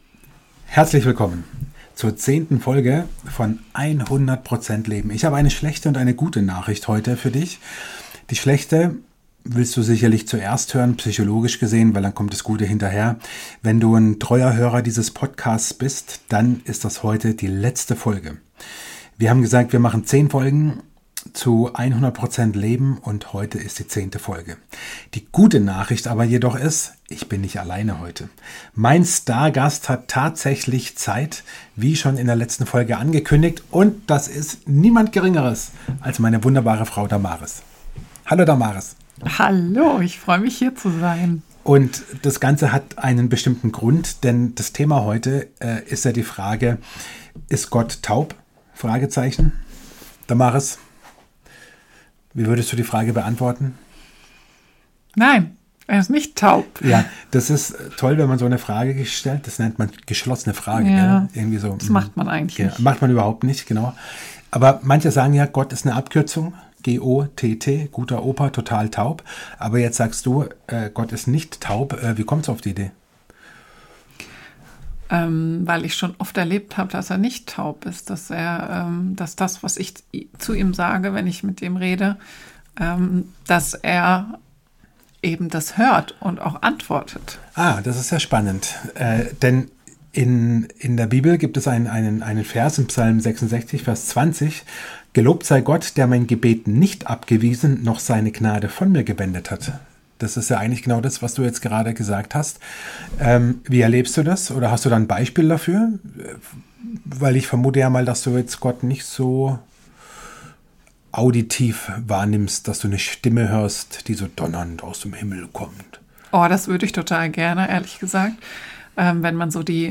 In dieser Folge haben wir einen Gast am Start, dem das Beten sehr auf dem Herzen liegt. Sie nimmt dich mit hinein in das, was sie mit dem Beten so erlebt.